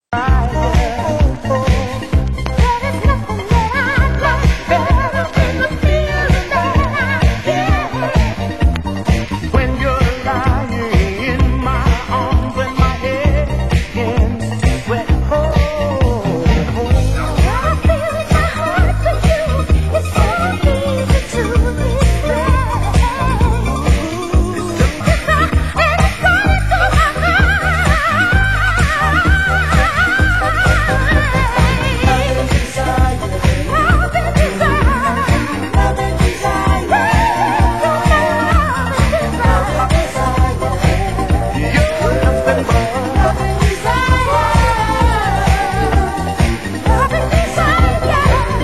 Genre: Electro